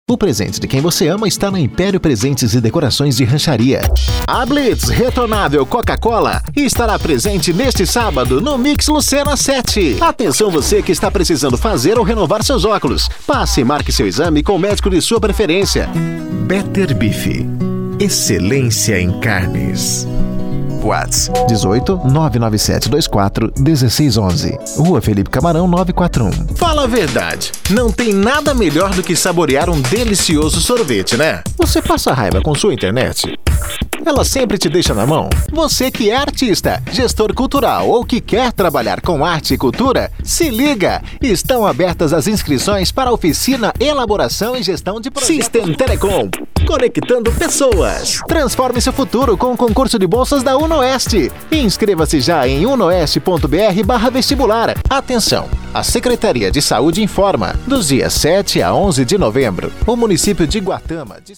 PADRAO